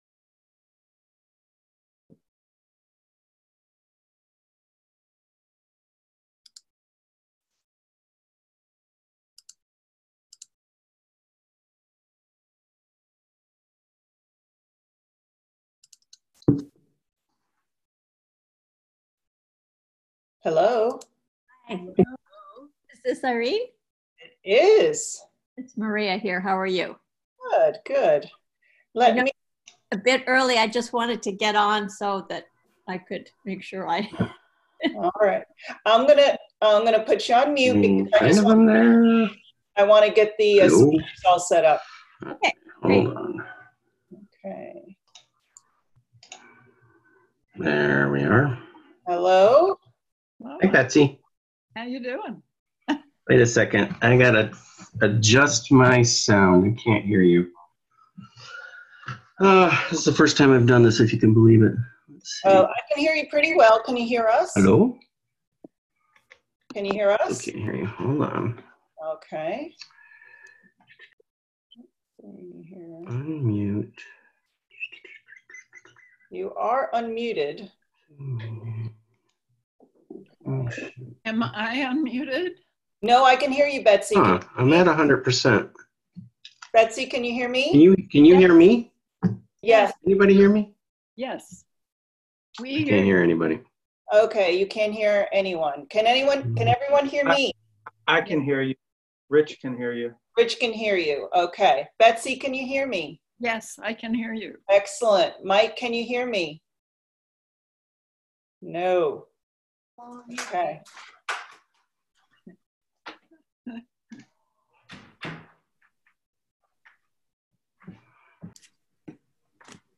Bringing Climate Solutions Home Online Panel Discussion - April 30, 2020